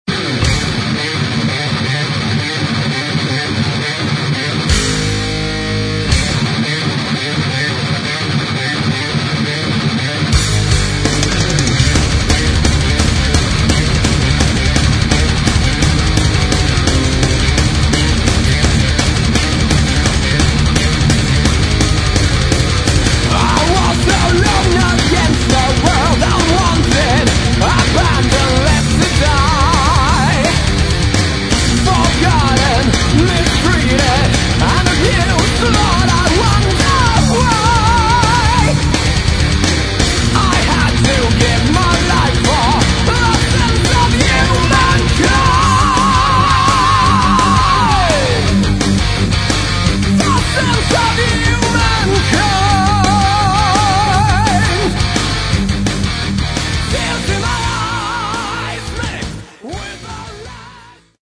Metal
гитара
барабаны
вокал
бас